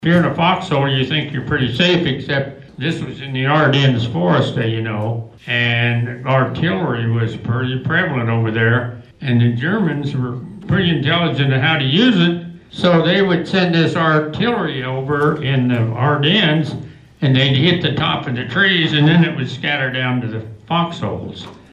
Stories were shared during a panel discussion Saturday at the American Legion.